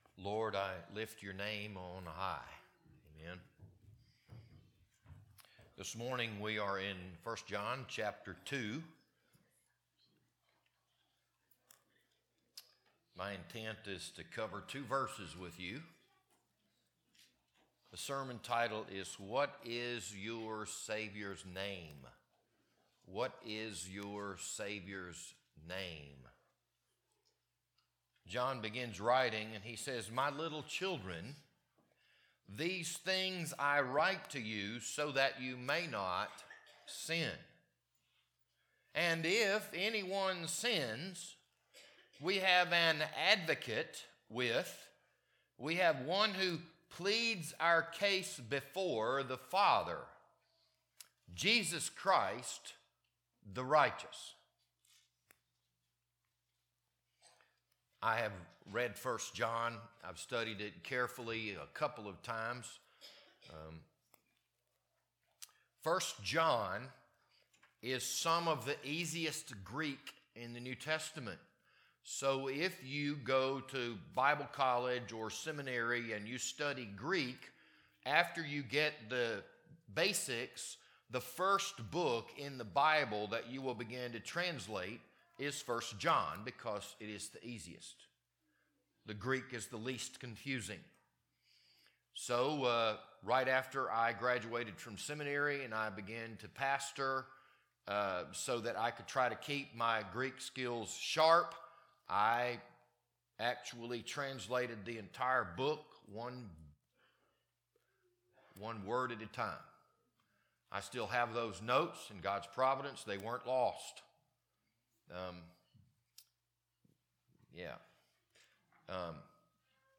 This Sunday morning sermon was recorded on January 18th, 2026.